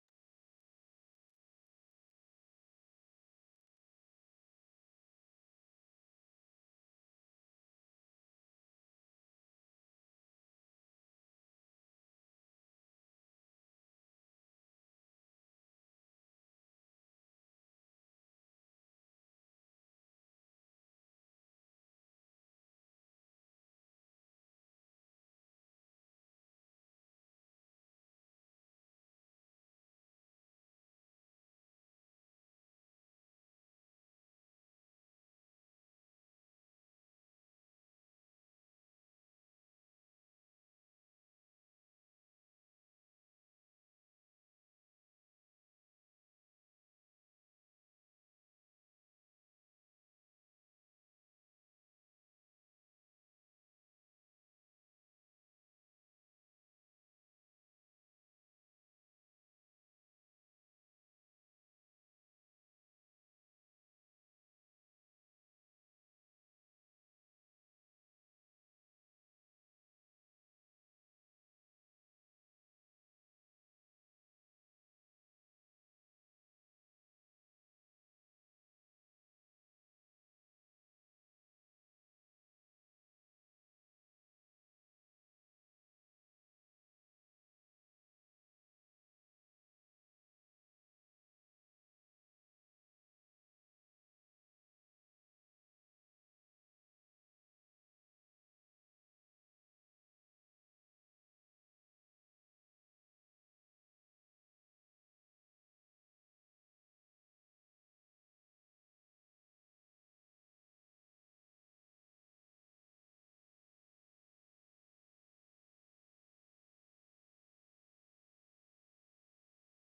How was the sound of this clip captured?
MIFC+x+EFC+Town+Hall+Audio.m4a